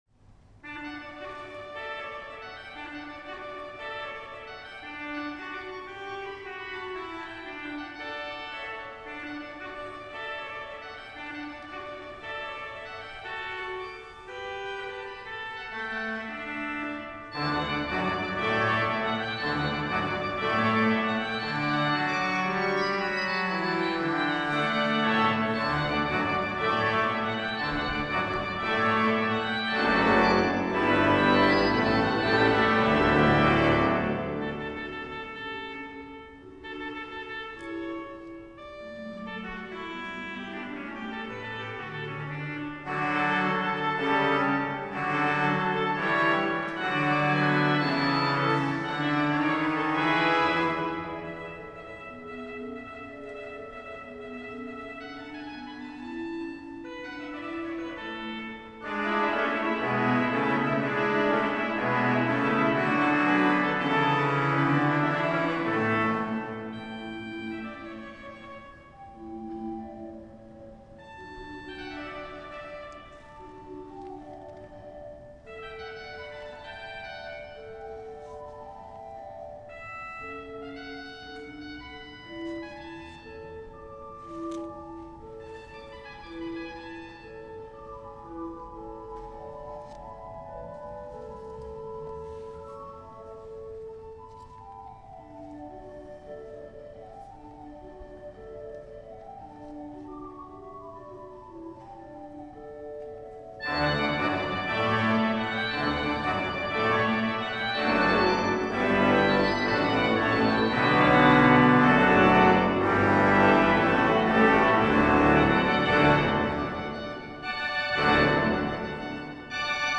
Concert sur l'orgue Callinet de l'église Notre-Dame du Marthuret à Riom
Les extraits montrent quelques échantillons des sonorités particulières de l'orgue.